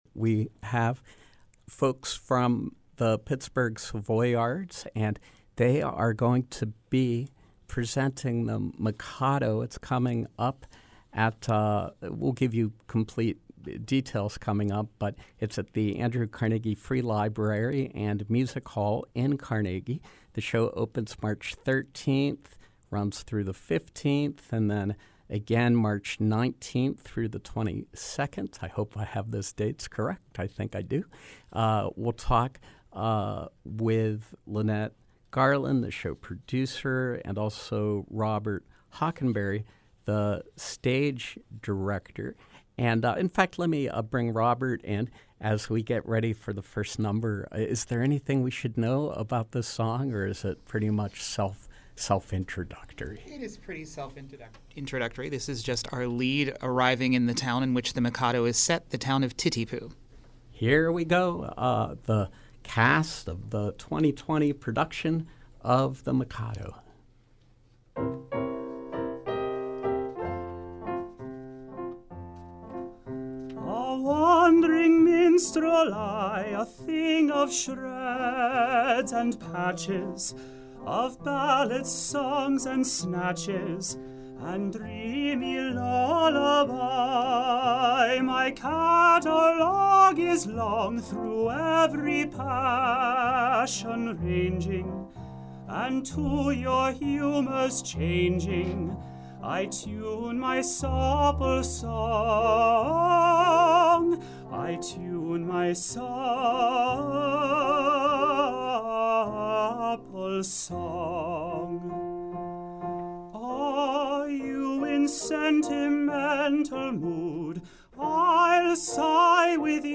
Live Performance: The Mikado, Pittsburgh Savoyards